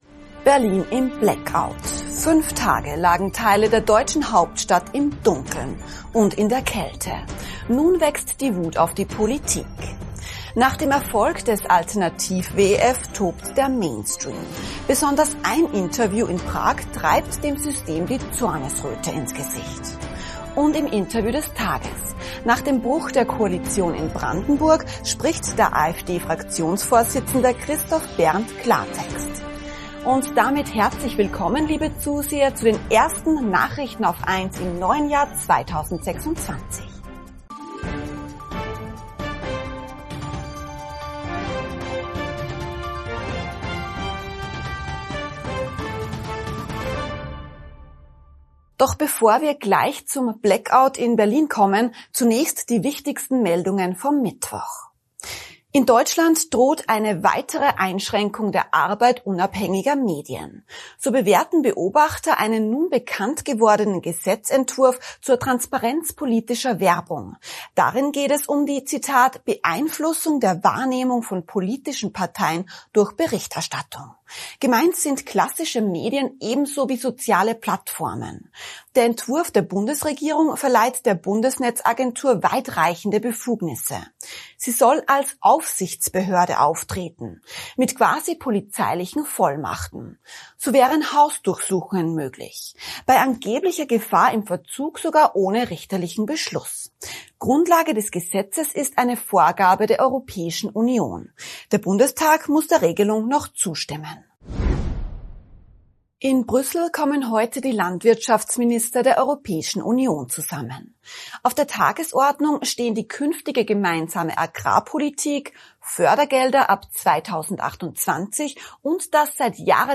Besonders ein Interview in Prag treibt dem System die Zornesröte ins Gesicht + Und im Interview des Tages: Nach dem Bruch der Koalition in Brandenburg spricht der AfD-Fraktionsvorsitzende Christoph Berndt Klartext.